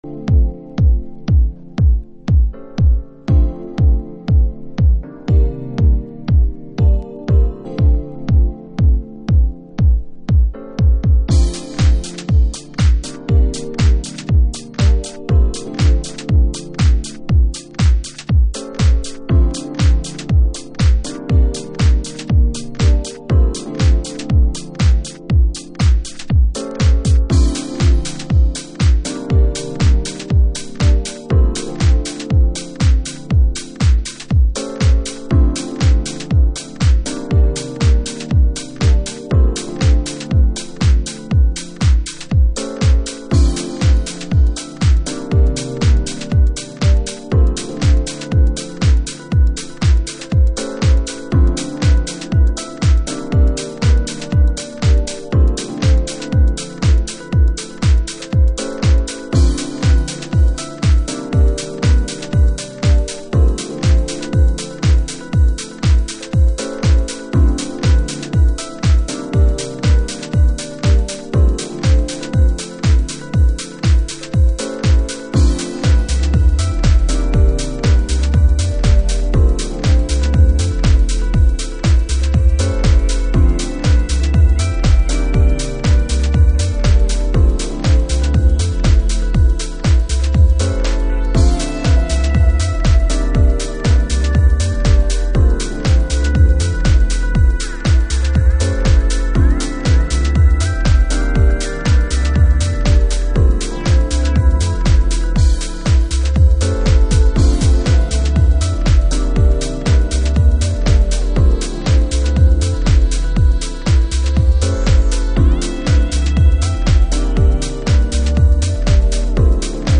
TOP > House / Techno > VARIOUS